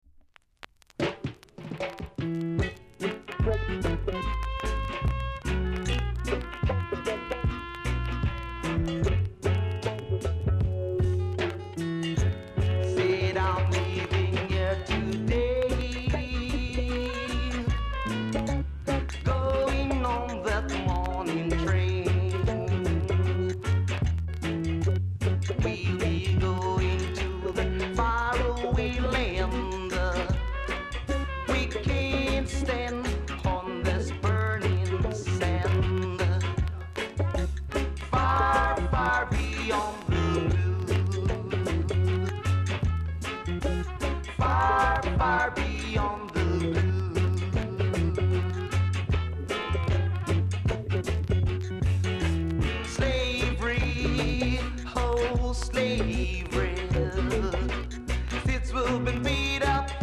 ※チリ、パチノイズが単発で所々あります。
コメント DEEP ROOTS!!RARE!!※裏面に目立つ傷ありノイズ大きめです。